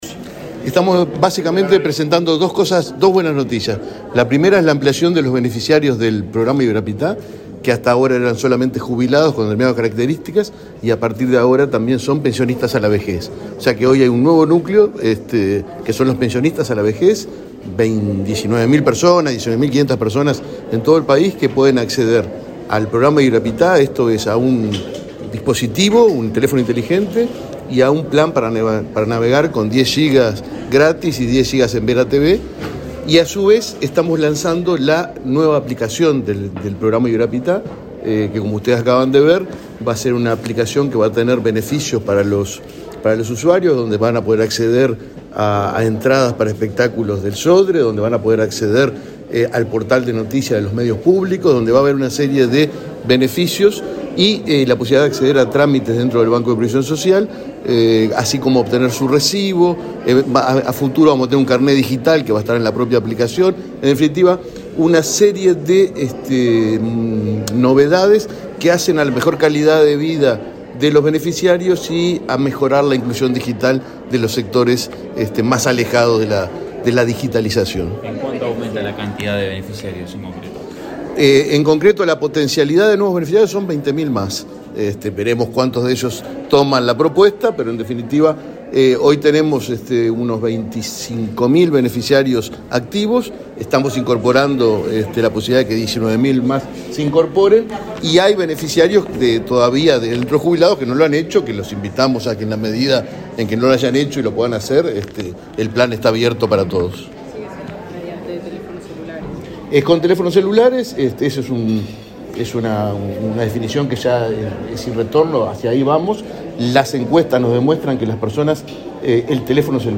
Declaraciones del presidente del BPS, Alfredo Cabrera
Declaraciones del presidente del BPS, Alfredo Cabrera 24/10/2023 Compartir Facebook Twitter Copiar enlace WhatsApp LinkedIn El Banco de Previsión Social (BPS) lanzó la aplicación móvil Ibirapitá y firmó convenios con Medios Públicos, ACAU y MEC. Su titular, Fernando Cabrera, dialogó con la prensa luego del acto.